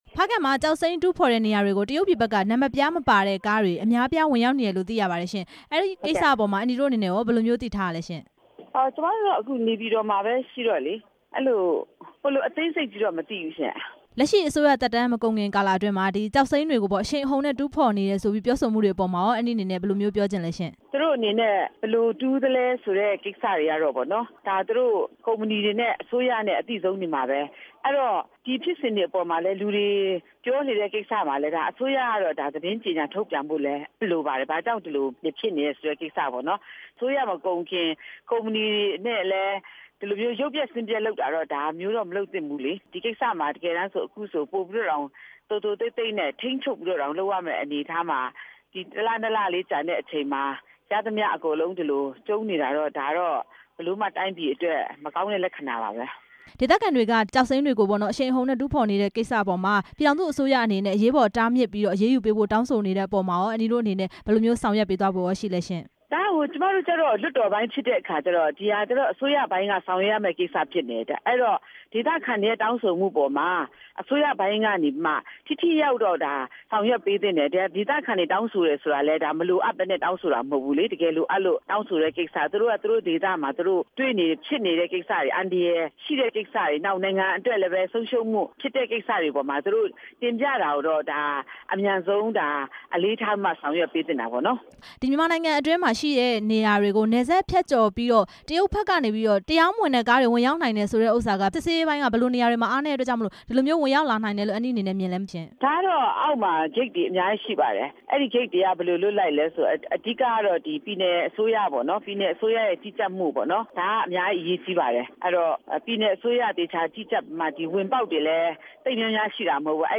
ဖားကန့်မှာ နံပါတ်ပြားမပါတဲ့ ကားတွေ ဝင်ရောက်နေတဲ့ အကြောင်း မေးမြန်းချက်